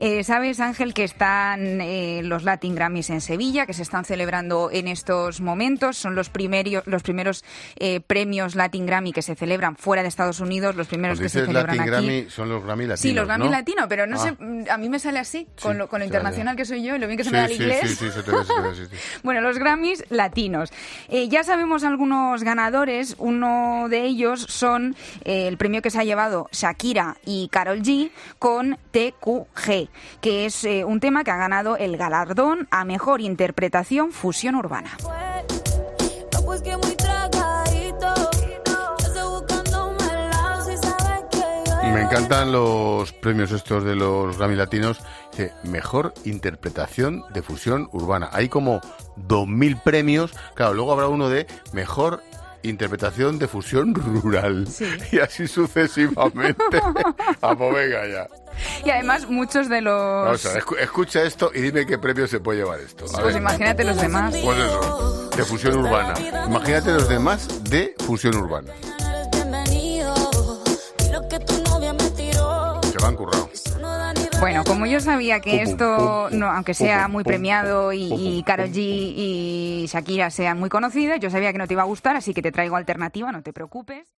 Las críticas de Expósito en directo al escuchar a uno de los grandes ganadores de los Latin Grammy